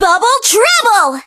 bibi_ulti_vo_02.ogg